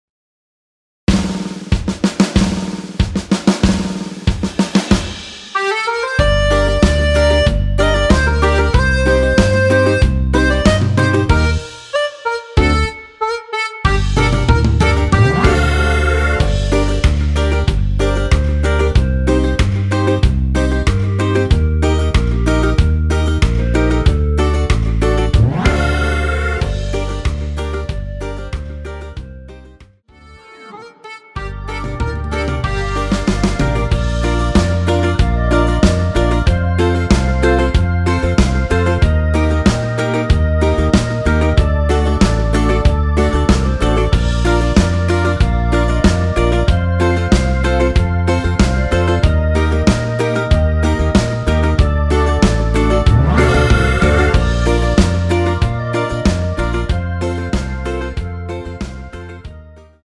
원키에서(+1)올린 멜로디 포함된 MR입니다.
Gm
◈ 곡명 옆 (-1)은 반음 내림, (+1)은 반음 올림 입니다.
앞부분30초, 뒷부분30초씩 편집해서 올려 드리고 있습니다.
중간에 음이 끈어지고 다시 나오는 이유는